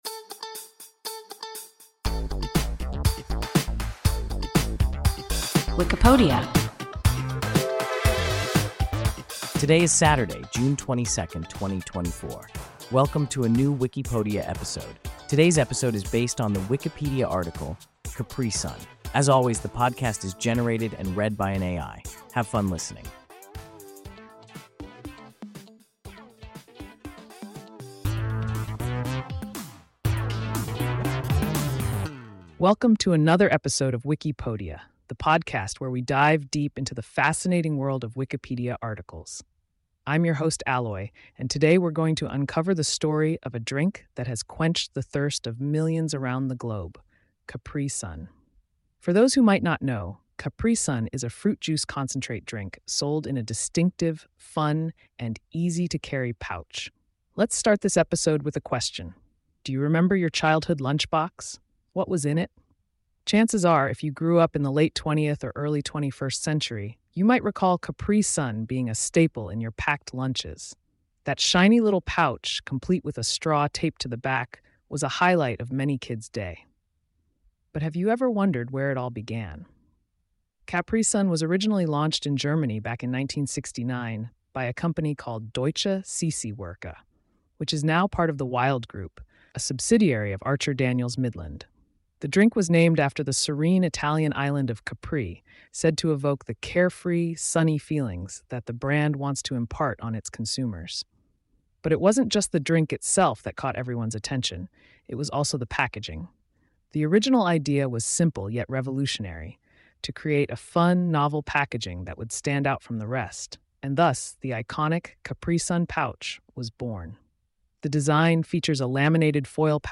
Capri-Sun – WIKIPODIA – ein KI Podcast